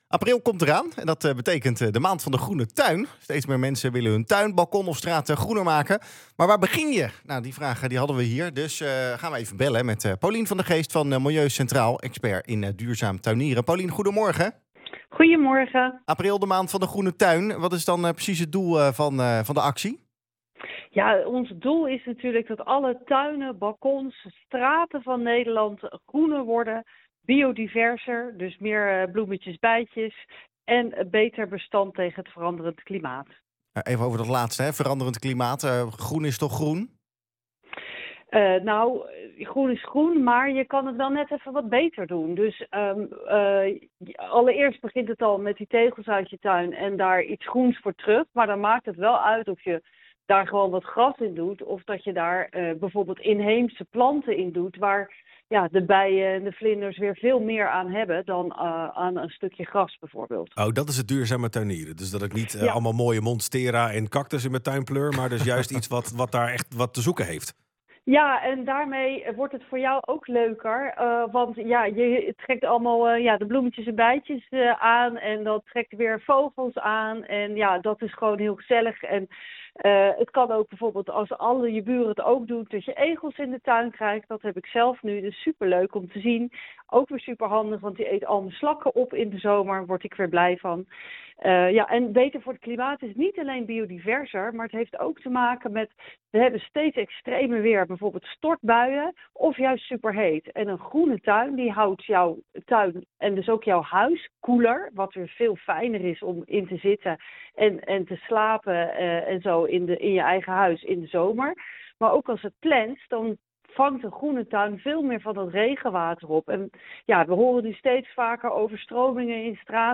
vertelt over de Maand van de Groene Tuin in de Centraal+ Ochtendshow: